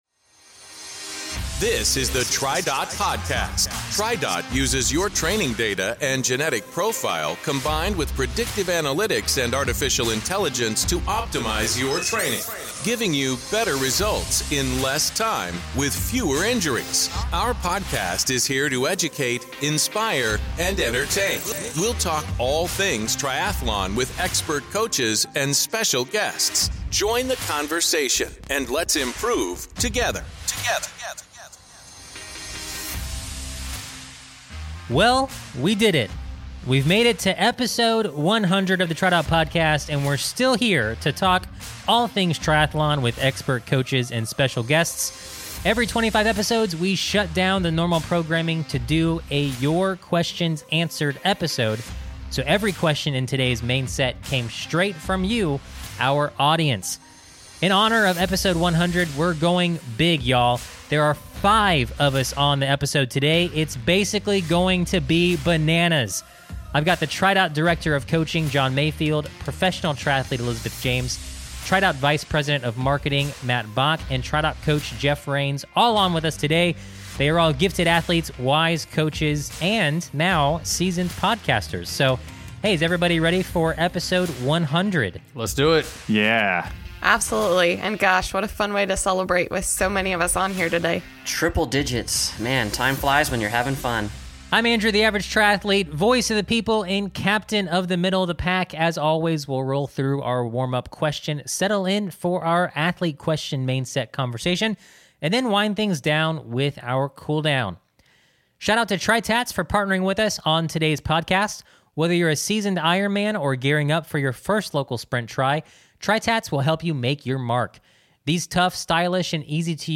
We're celebrating episode 100 with our coaches answering your questions!